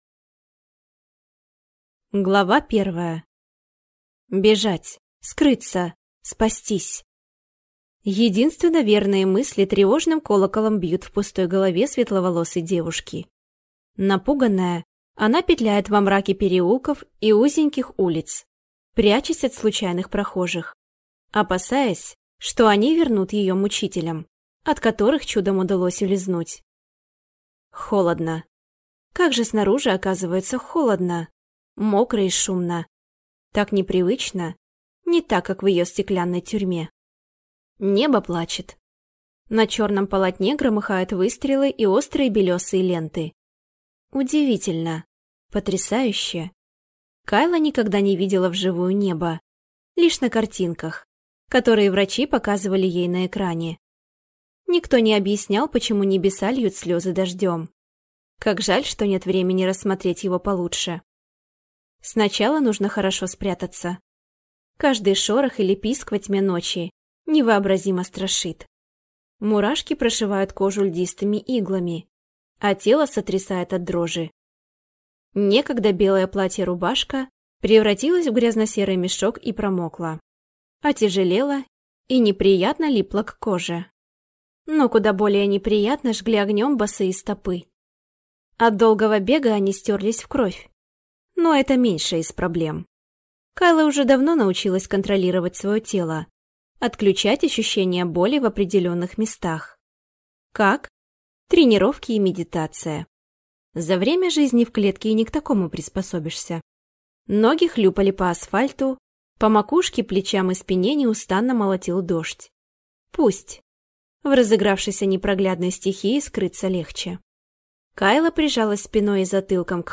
Аудиокнига Новая Земля. Добыча зверя | Библиотека аудиокниг